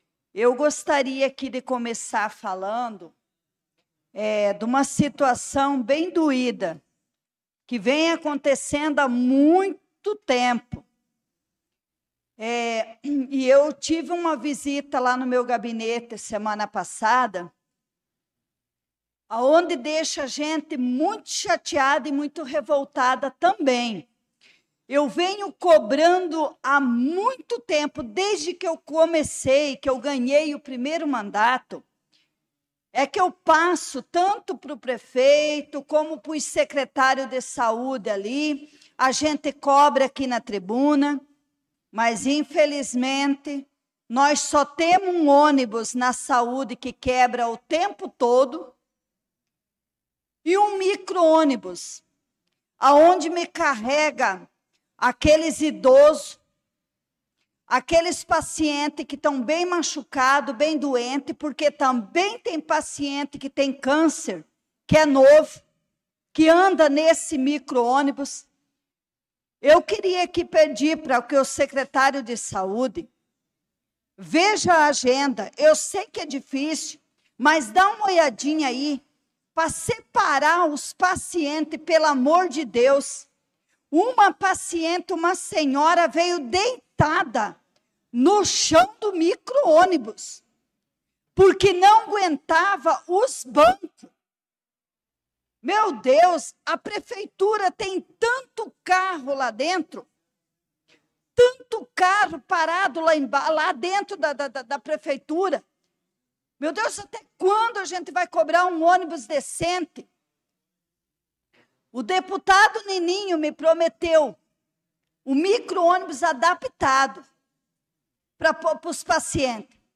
Pronunciamento da vereadora Leonice Klaus na Sessão Ordinária do dia 04/08/2025.